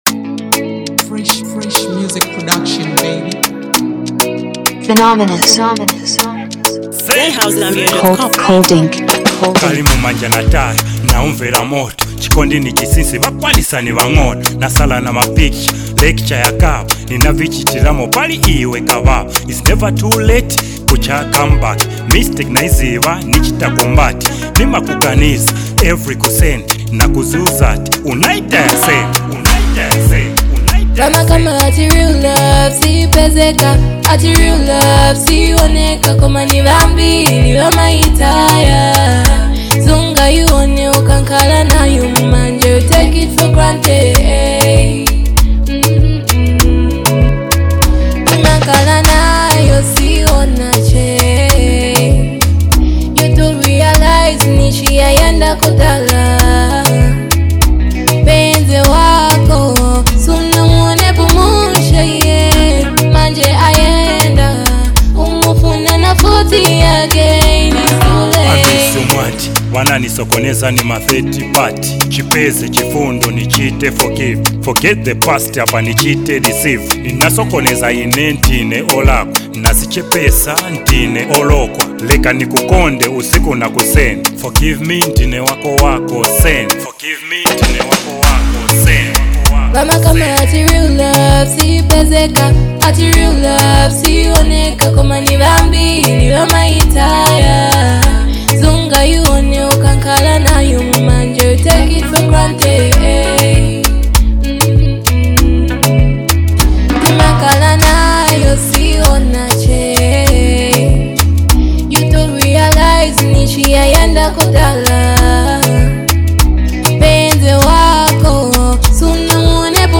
Their smooth vocals blend perfectly on this emotional track.